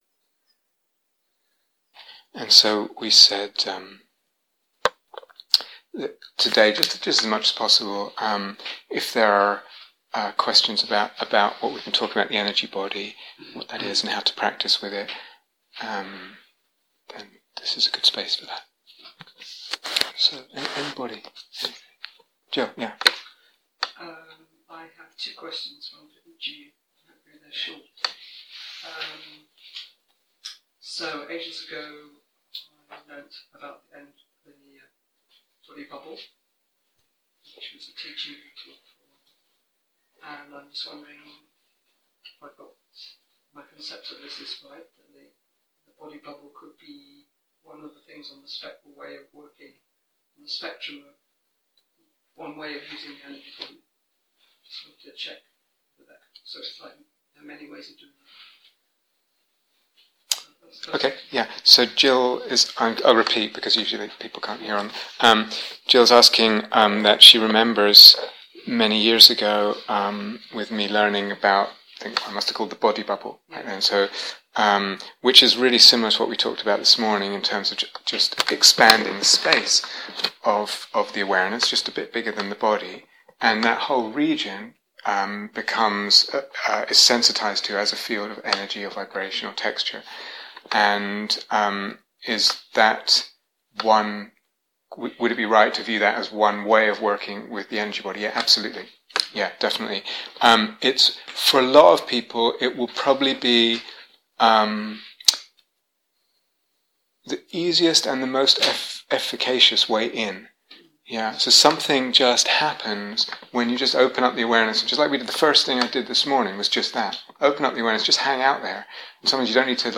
Growing the Energy Body (Q & A)